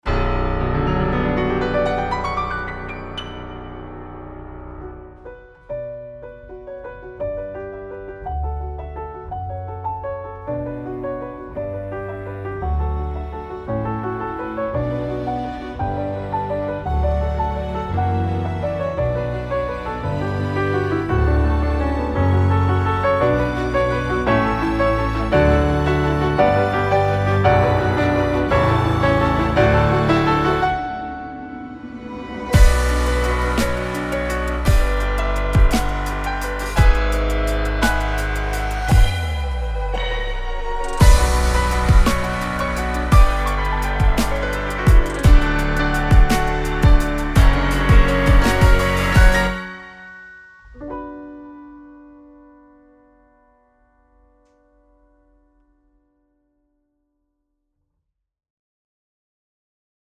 STUDIO-STANDARD PIANOS
• コンサバトリーグランド、現代的なアップライト、ビンテージの“Tom Thumb”ピアノ
• ソロ演奏や伴奏に最適な鮮明でバランスの取れた音色